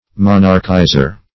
Monarchizer \Mon"arch*i`zer\, n. One who monarchizes; also, a monarchist.